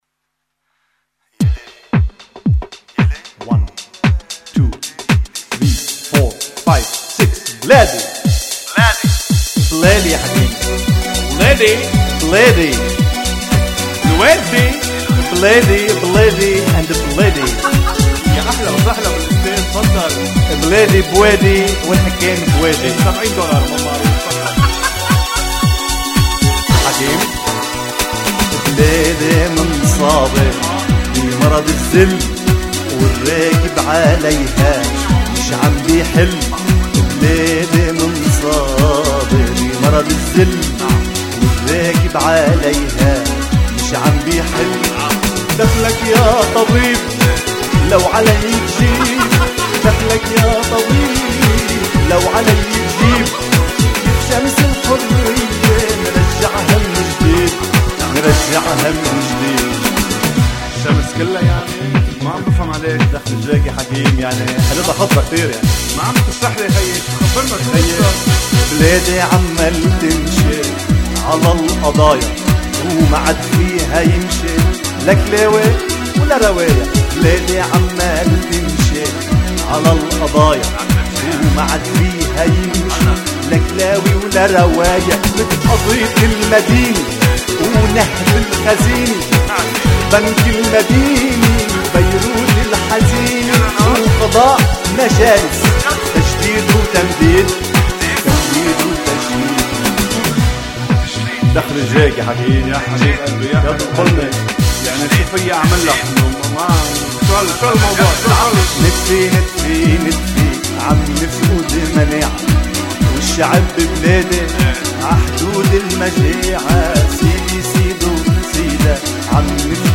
8. سجلت في فرنسا في   27ايلول 2004